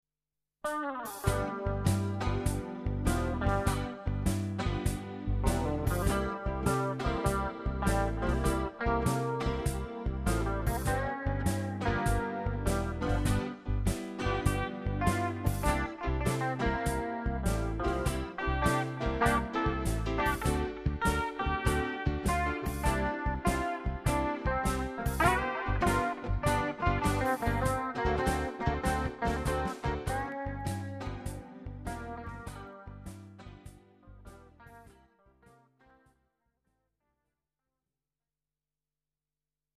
うわ！ギター下手過ぎ（笑）！！
セーブが出来ないものだから外部のハードディスクレコーダに録音
PseudoBlues.mp3